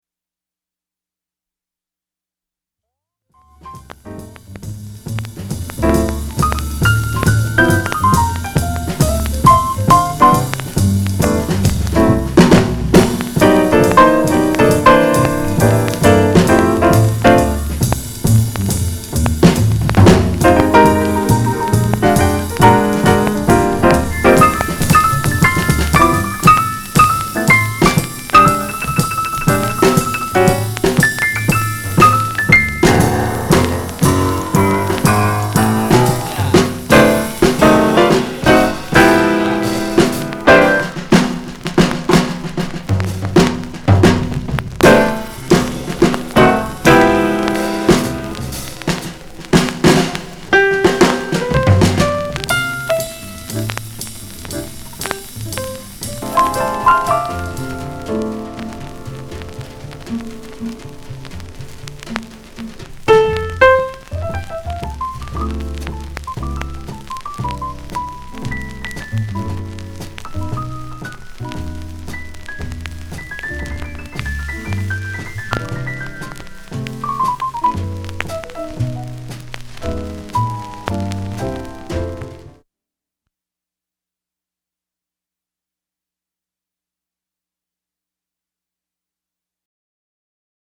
当前位置 > 首页 >音乐 >唱片 >爵士乐 >一般爵士